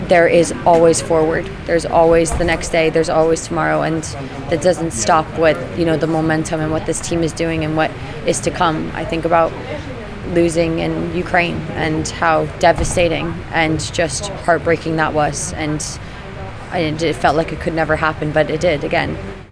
Striker Kyra Carusa says they need to use this disappointment as fuel for the next World Cup qualifiers…